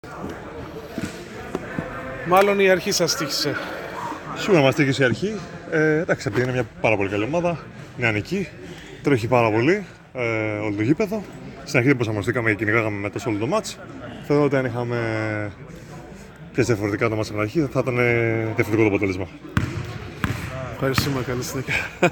GAMES INTERVIEWS
Παίκτης Motor Oil